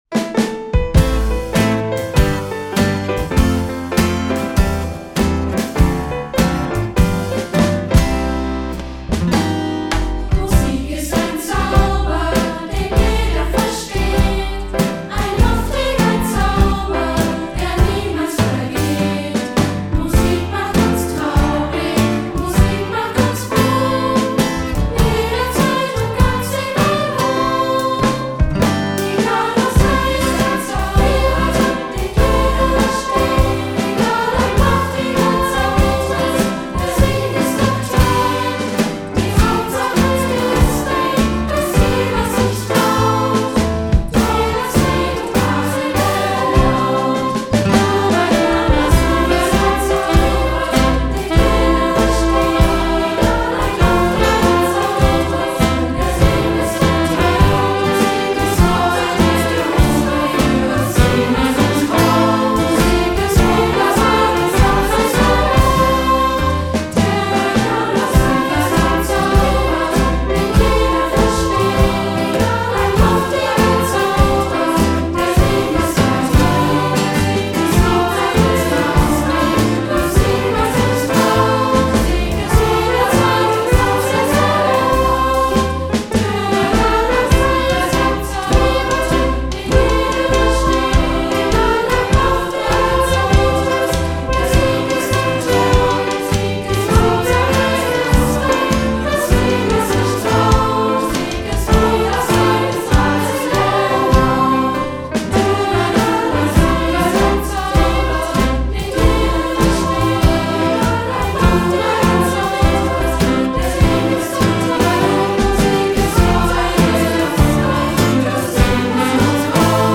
Einspielung als Kanon